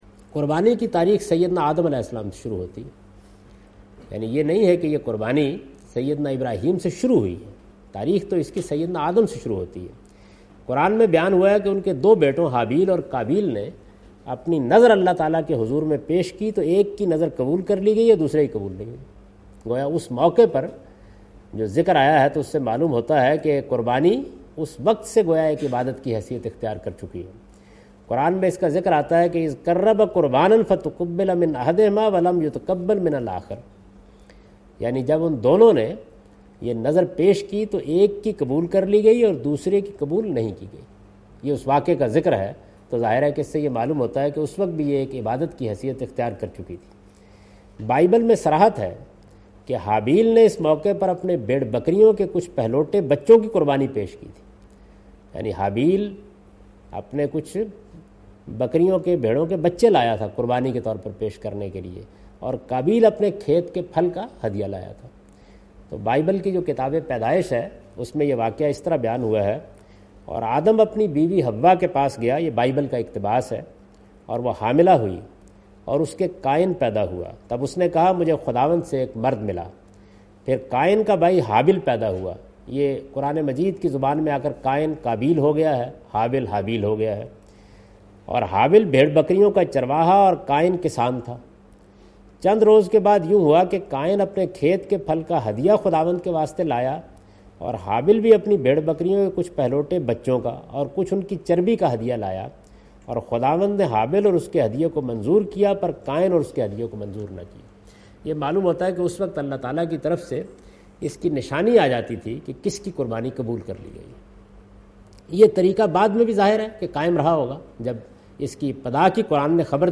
In this video of Hajj and Umrah, Javed Ahmed Ghamdi is talking about "History of Animal Sacrifice".